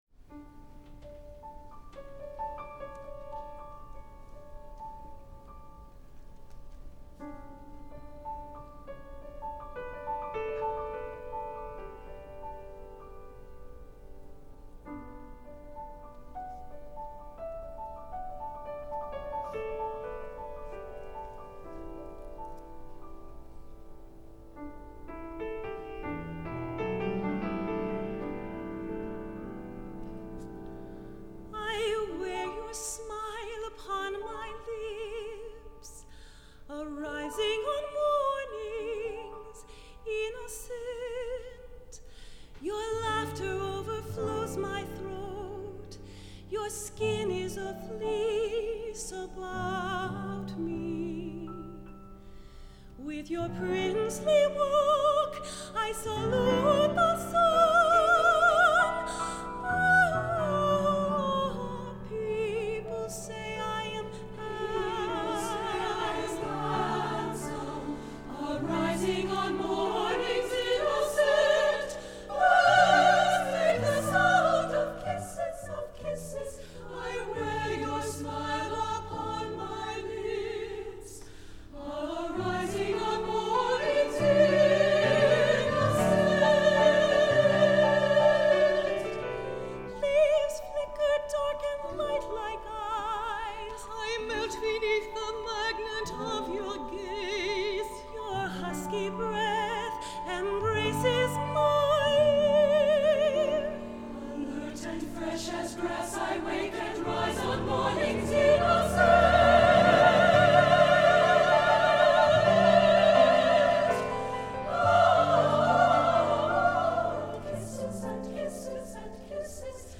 for SSAA Chorus and Piano (1993)